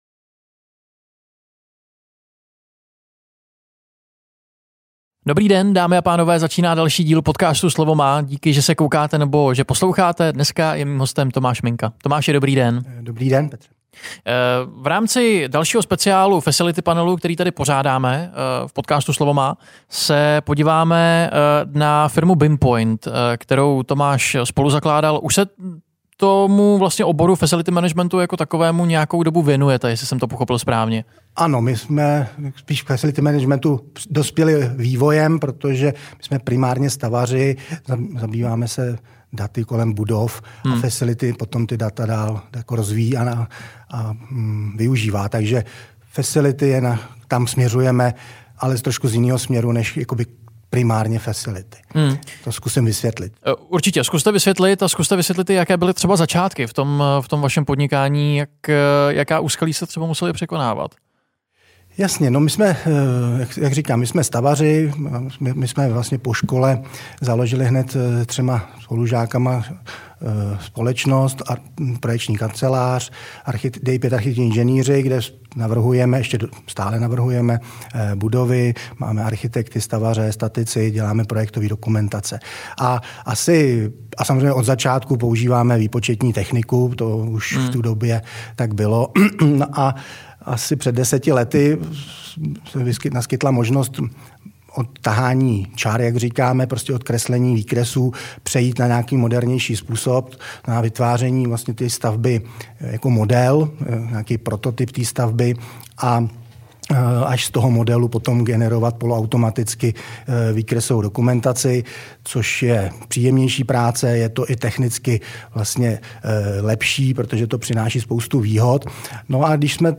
Rozhovor vznikl v rámci nového projektu Smart Building Club, který se stará o propojování a edukaci firem v oblasti správy budov a majetku.